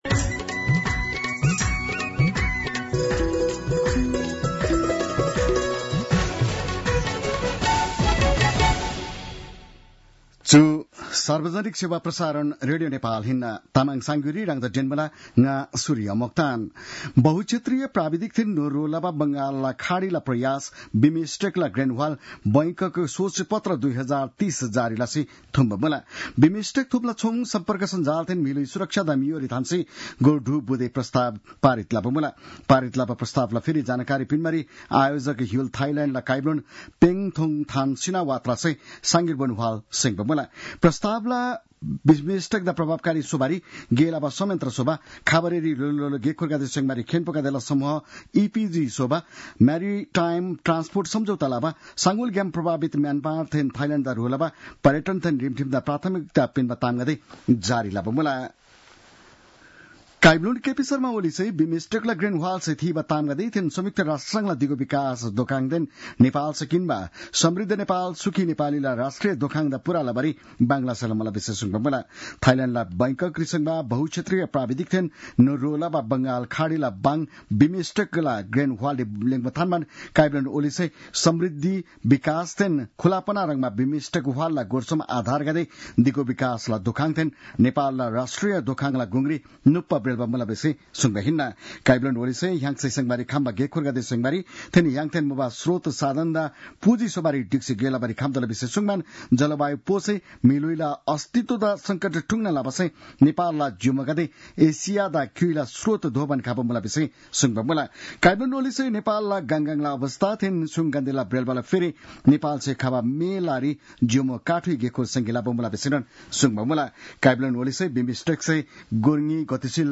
An online outlet of Nepal's national radio broadcaster
तामाङ भाषाको समाचार : २२ चैत , २०८१